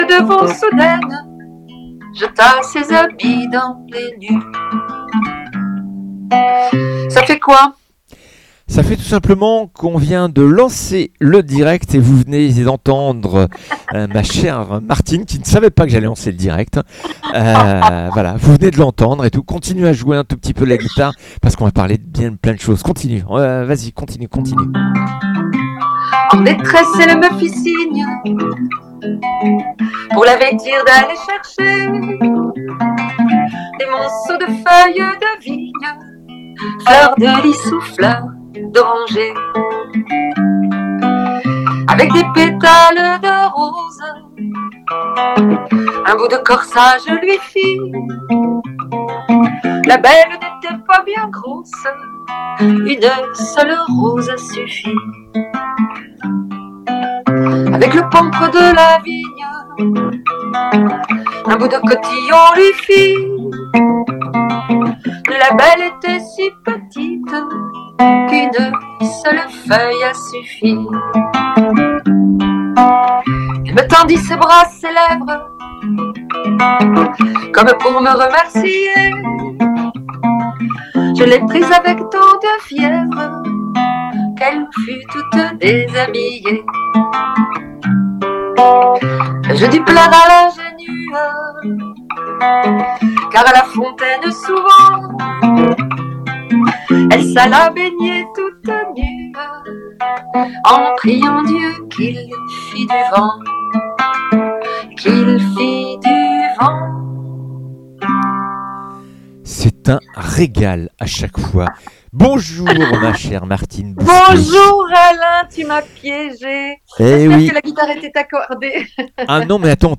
J’ai l’immense honneur de chanter en Italie à Torre Pellice en Piémont dans le cadre de la semaine de la langue française